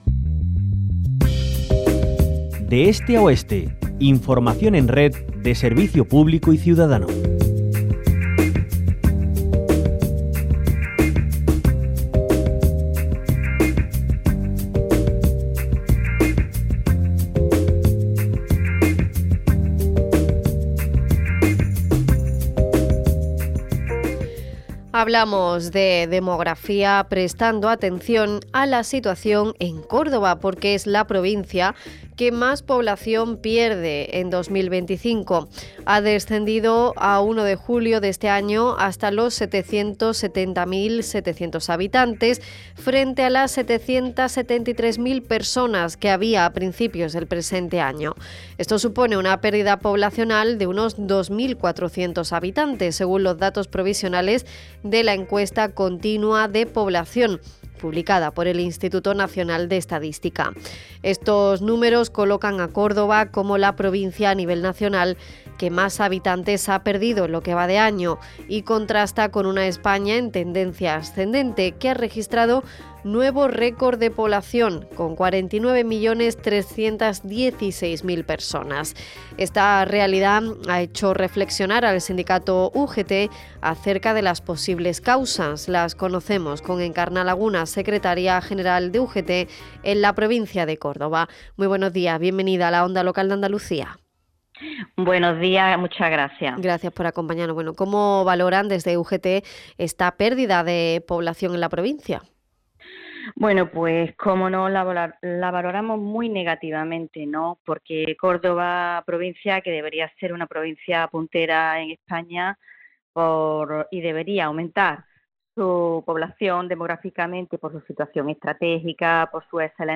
La entrevista en radio